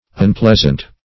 Unpleasant \Un*pleas"ant\, a.